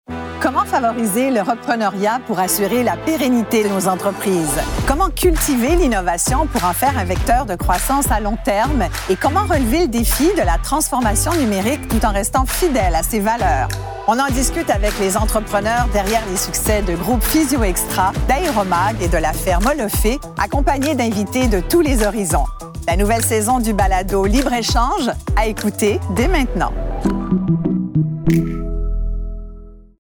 Bande-annonce : Libres échanges, saison 4
Isabelle Maréchal reçoit les grands joueurs derrière les succès de la Ferme Olofée, d’Aeromag et de Groupe PhysioExtra.